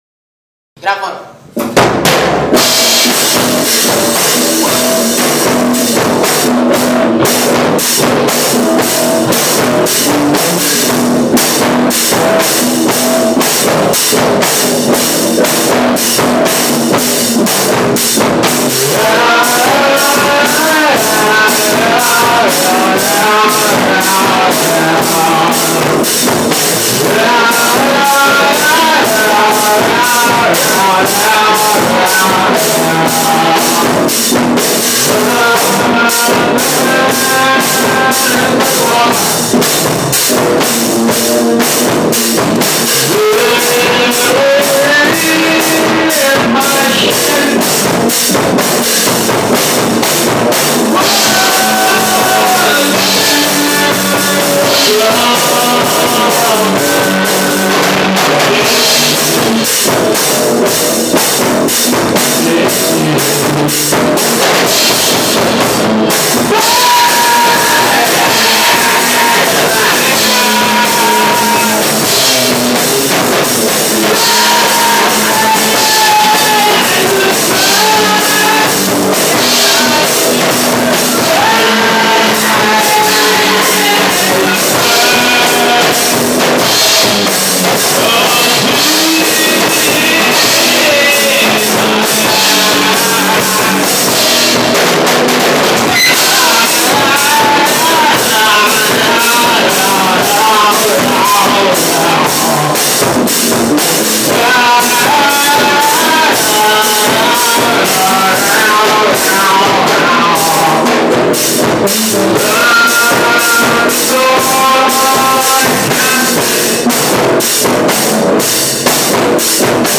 cover song.